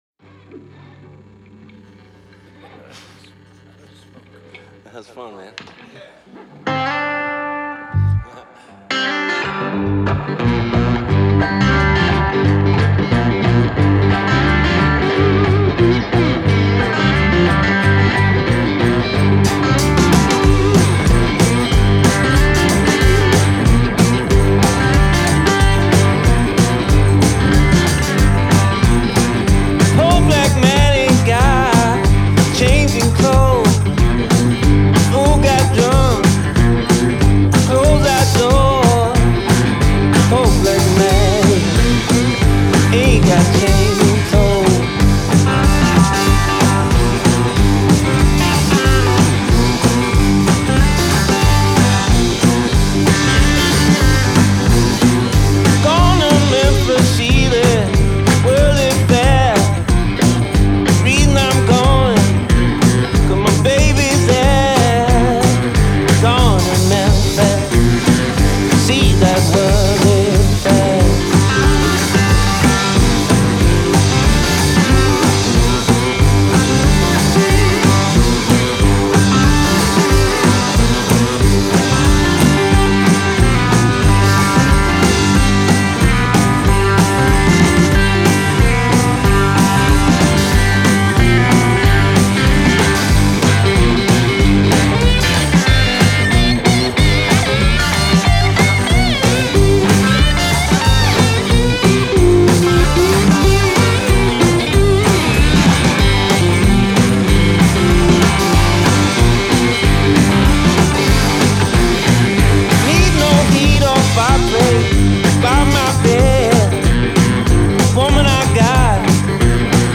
Genre : Blues, Rock